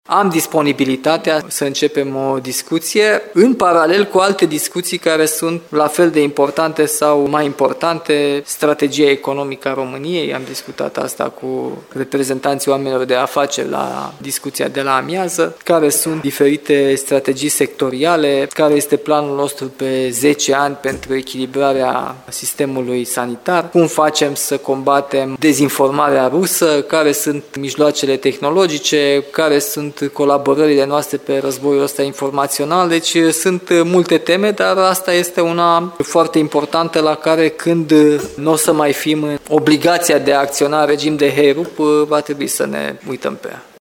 Aflat în prima vizită oficială la Timișoara, președintele Nicușor Dan a explicat, răspunzând unei întrebări din partea Radio Timișoara, că o discuție pe această temă poate fi purtată doar după rezolvarea problemelor urgente.